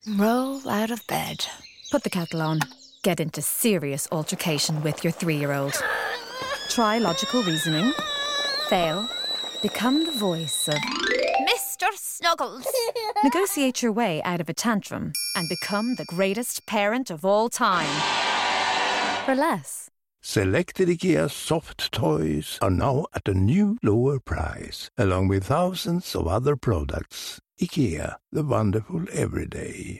Our standout radio ad for August is “Toys” from IKEA.
The ad finishes with the famous tagline “The wonderful everyday” which is delivered in an unmistakeable Swedish voice, reinforcing the brand’s strong identity and ensuring it’s easily recognised.